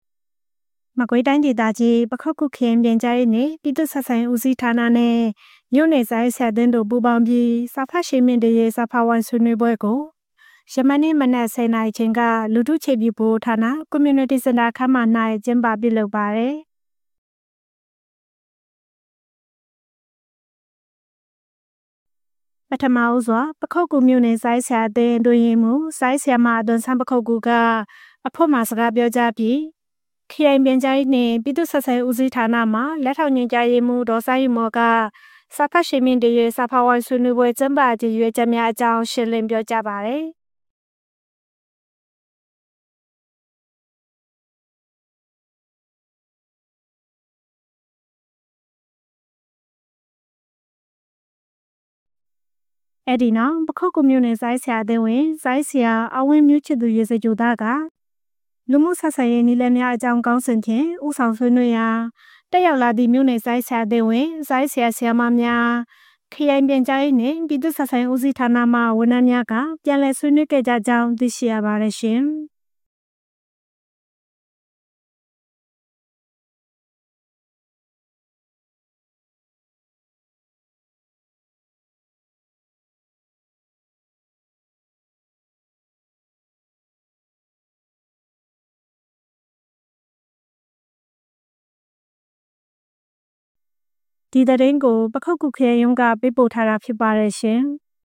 ပခုက္ကူမြို့၊လူထုအခြေပြုဗဟိုဌာန၌ စာဖတ်ရှိန်မြှင့်တင်ရေးစာဖတ်ဝိုင်းဆွေးနွေးပွဲပြုလုပ် ပခုက္ကူ ဩဂုတ် ၁၇